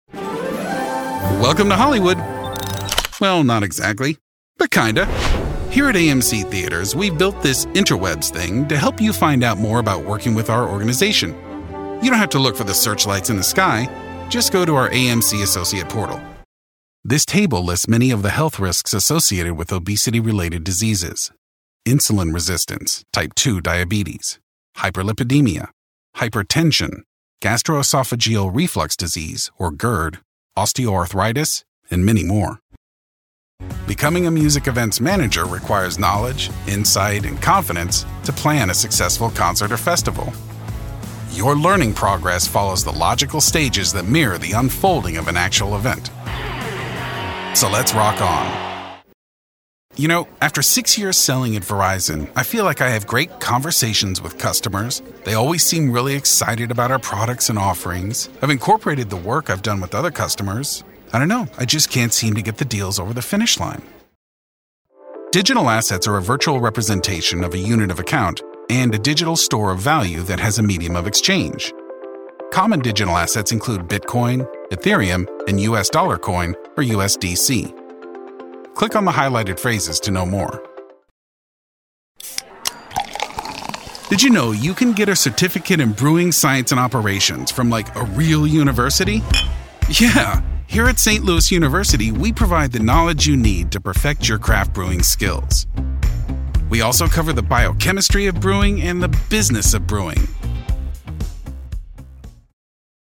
ELearning Demo
English - USA and Canada
Young Adult
Middle Aged